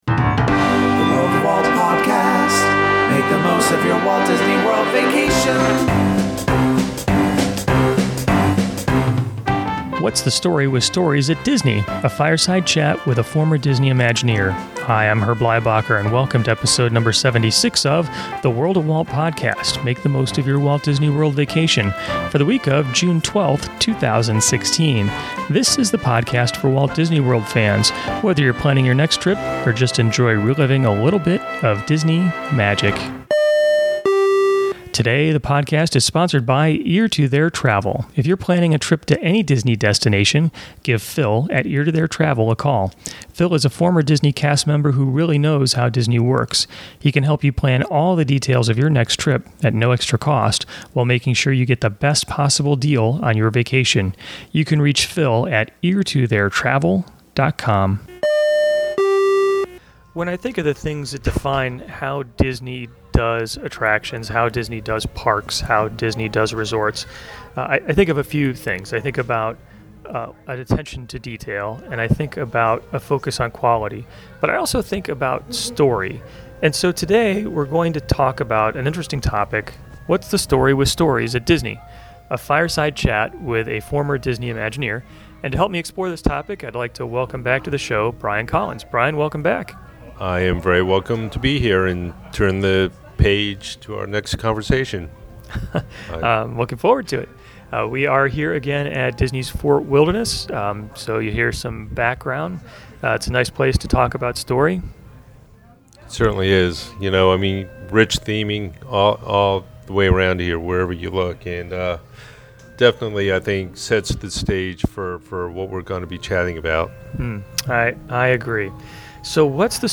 What’s The Story With Stories At Disney – A Fireside Chat